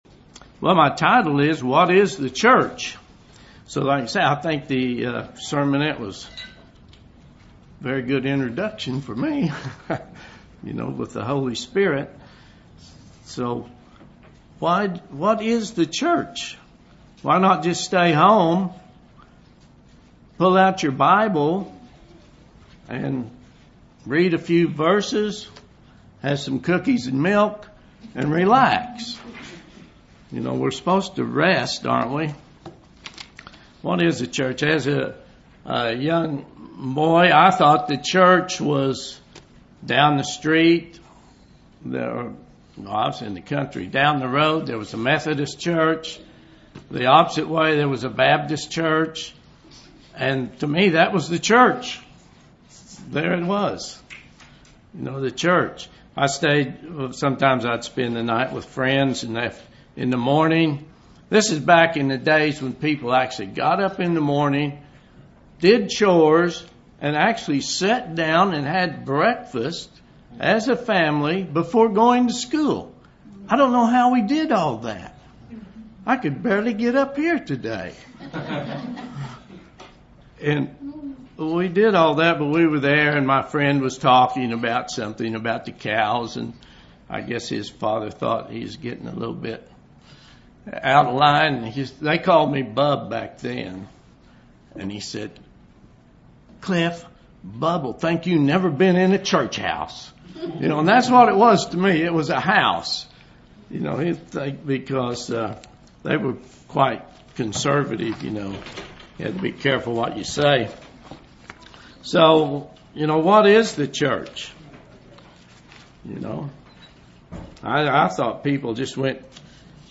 A Pentacost sermon that describes what the Church is.